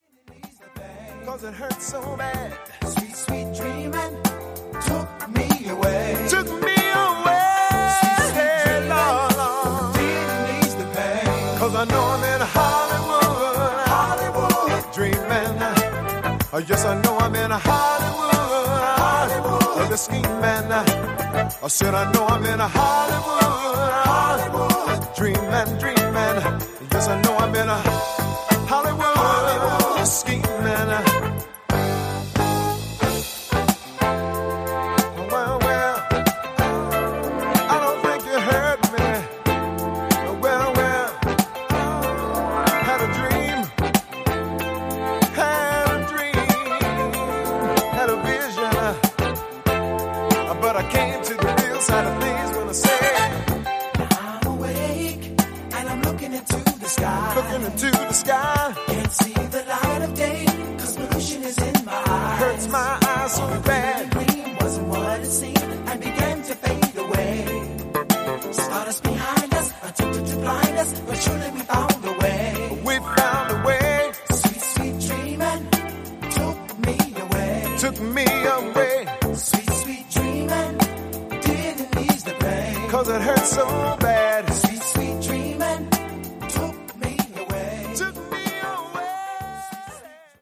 US Funk band